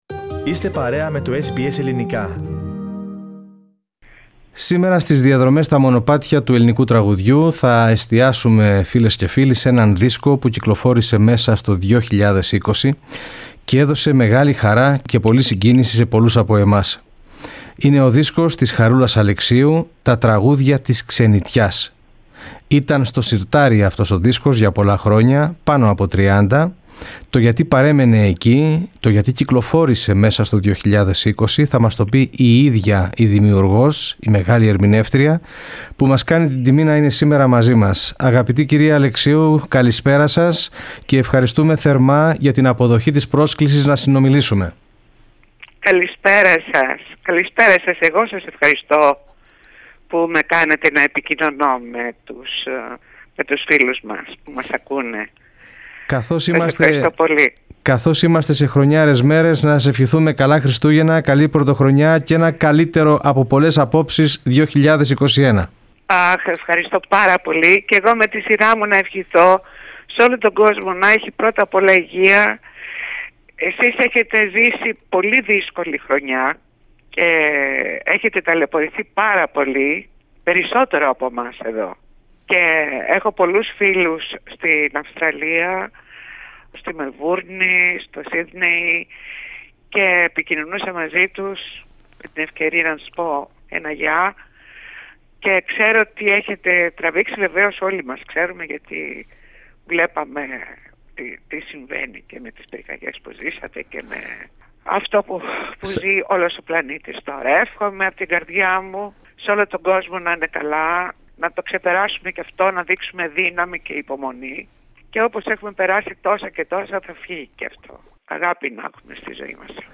Διπλά εορταστική συνέντευξη με τη Χαρούλα Αλεξίου